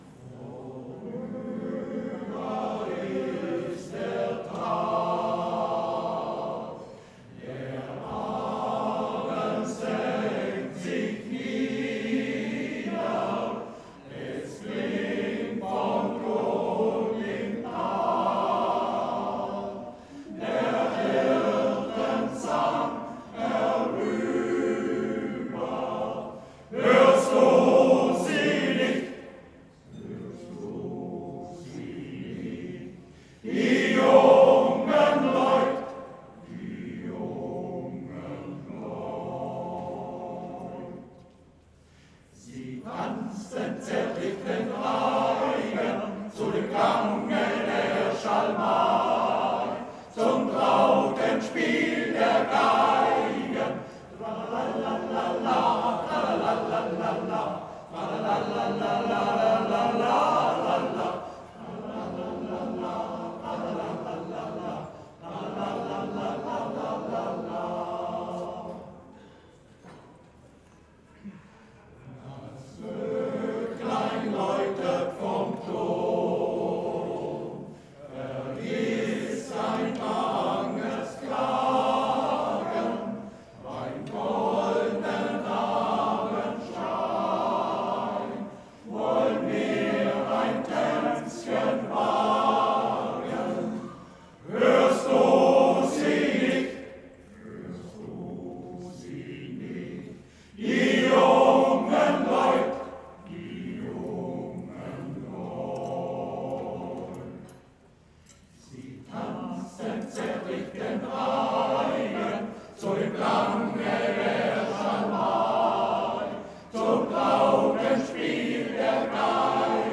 Sängerkranz Alfdorf – Abend im Gebirge (21.01.2017 Jahresfeier)